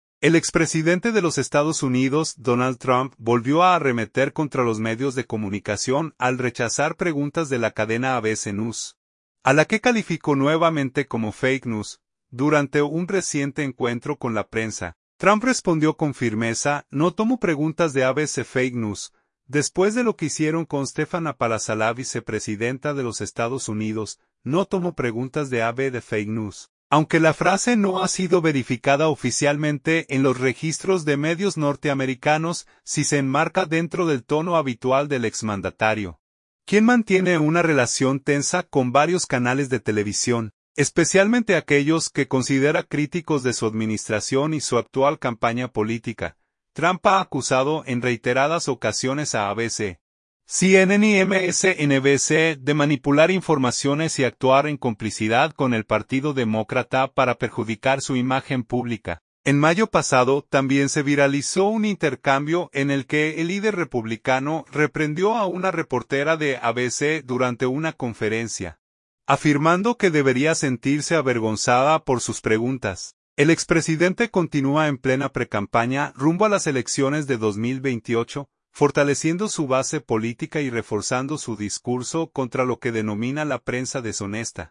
Durante un reciente encuentro con la prensa, Trump respondió con firmeza: